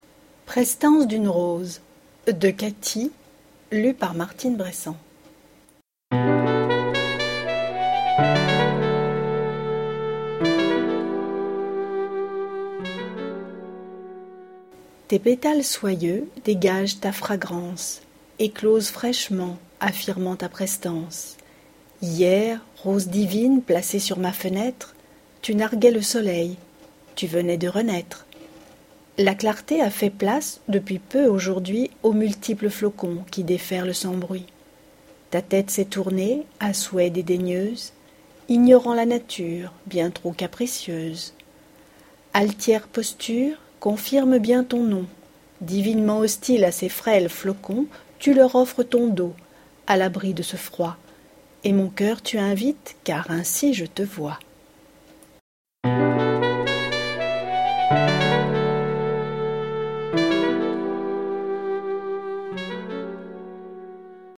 Lecture à haute voix
POEME